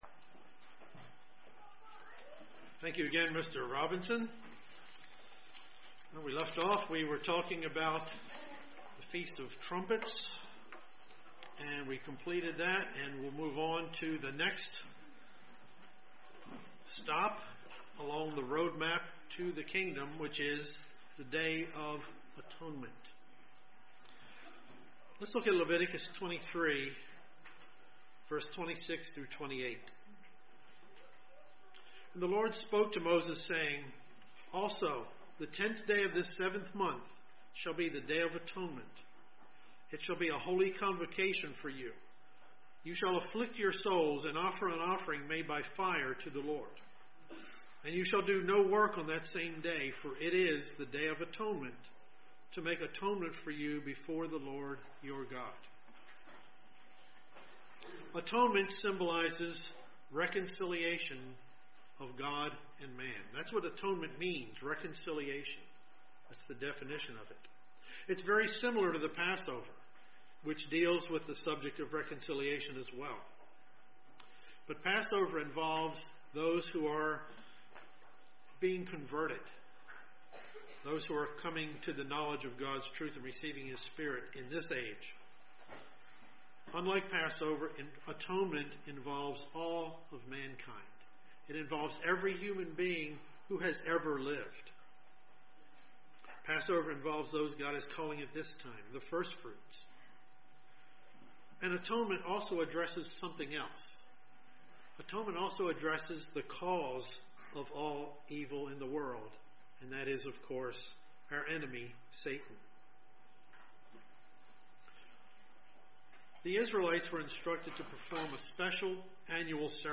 Part 2 of the Kingdom of God seminar that linked God's kingdom to the seven Holy Days given in Leviticus 23.
Given in Columbia, MD
UCG Sermon Studying the bible?